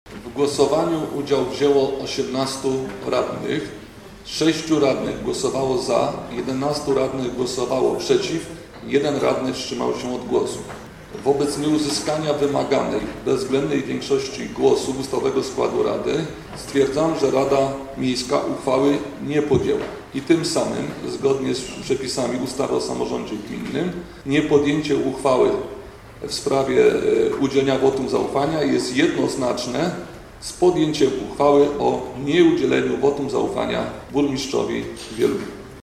Rada Miejska nie udziela burmistrzowi wotum zaufania – trwa sesja
Burmistrz Wielunia Paweł Okrasa nie dostał od Rady Miejskiej wotum zaufania. Mówi przewodniczący Piotr Radowski: